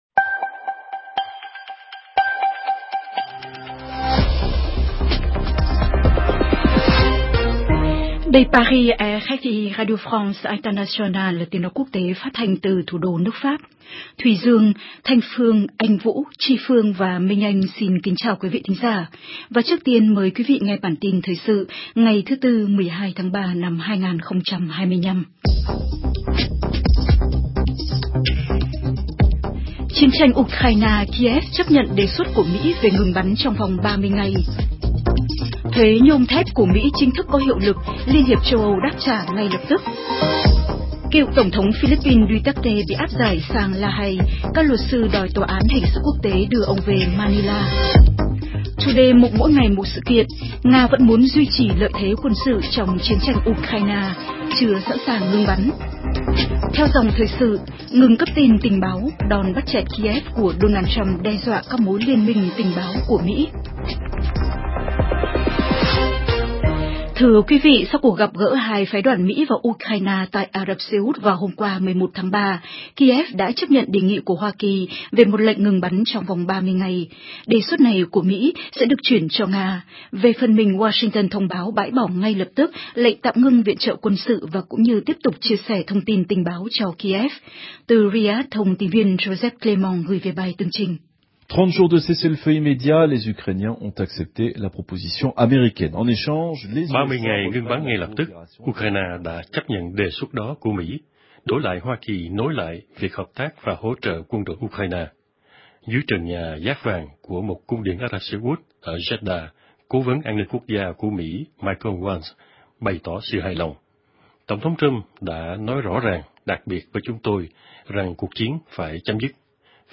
CHƯƠNG TRÌNH PHÁT THANH 60 PHÚT Xem tin trên website RFI Tiếng Việt Hoặc bấm vào đây để xem qua Facebook